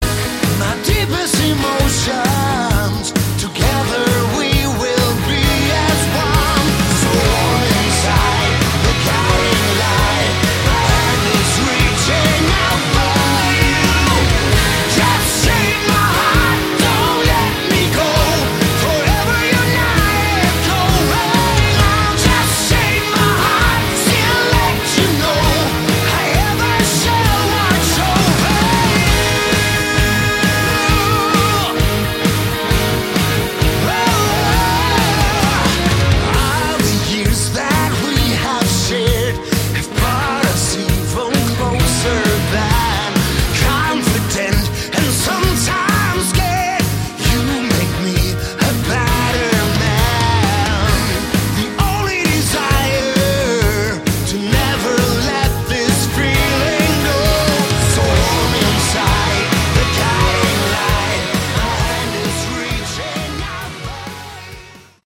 Category: Hard Rock
guitars
drums
vocals
bass
keyboards